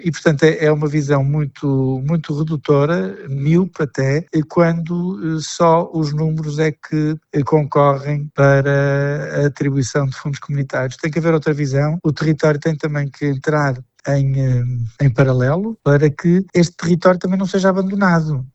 O também presidente da Câmara Municipal de Vila Flor argumenta que Trás-os-Montes representa 26% do território da região Norte, onde é produzida cerca de 50% da energia elétrica a nível nacional, concentrando ainda importantes reservas de água e uma produção agrícola e pecuária relevante: